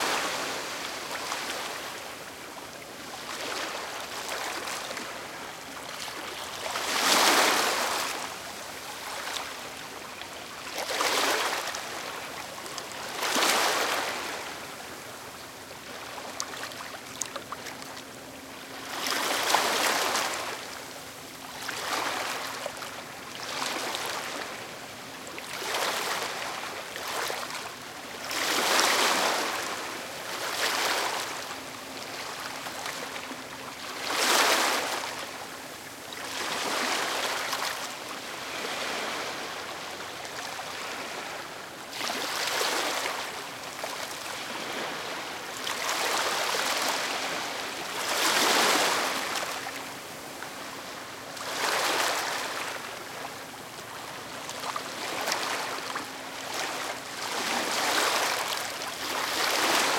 ocean.ogg